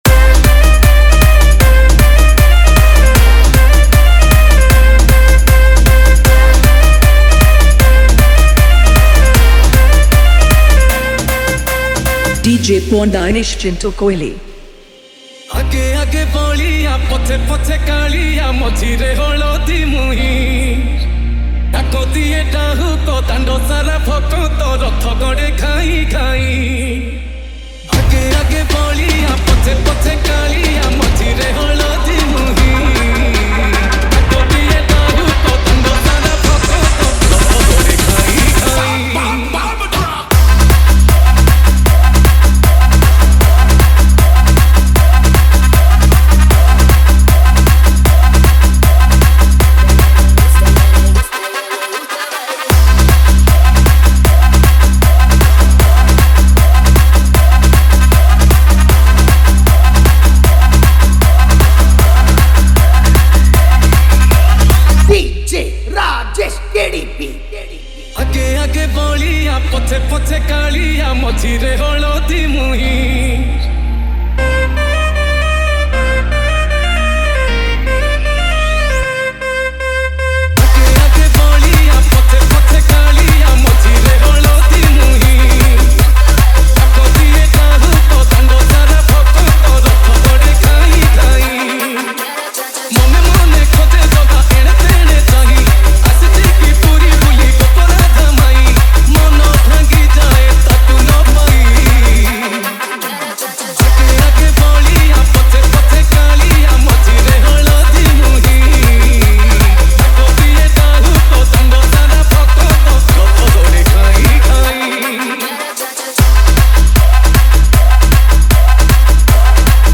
Category:  Odia Bhajan Dj 2024